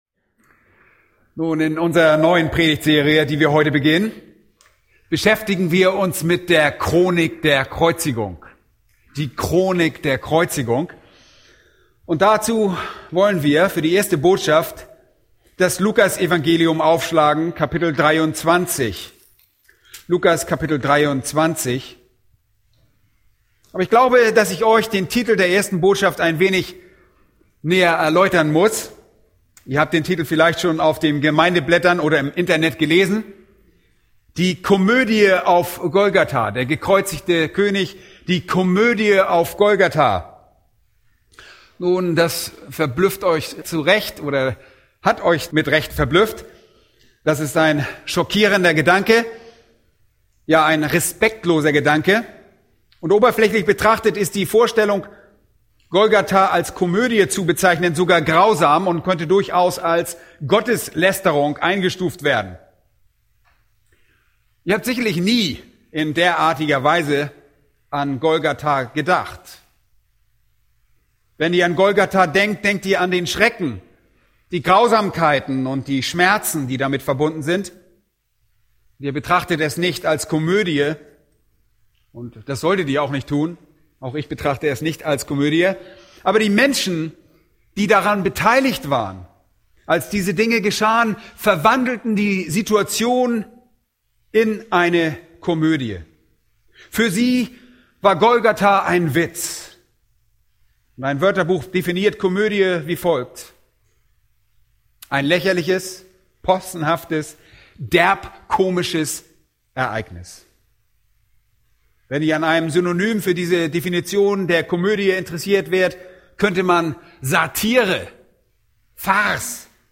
Aus der Serie: Die Chronik der Kreuzigung* | Weitere Predigten